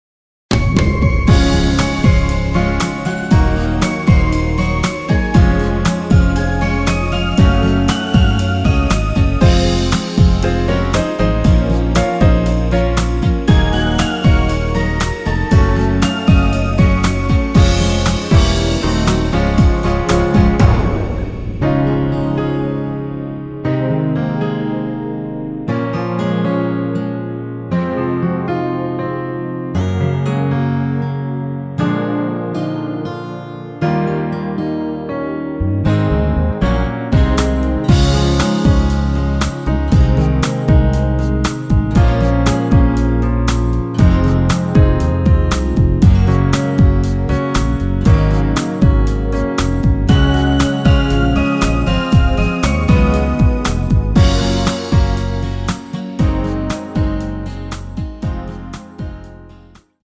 Db
앞부분30초, 뒷부분30초씩 편집해서 올려 드리고 있습니다.
중간에 음이 끈어지고 다시 나오는 이유는